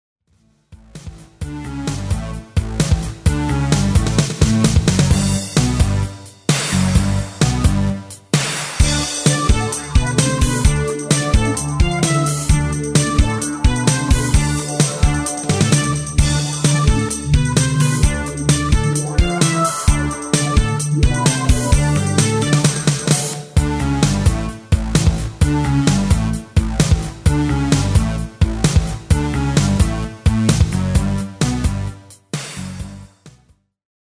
Demo/Koop midifile
- GM = General Midi level 1
- Géén vocal harmony tracks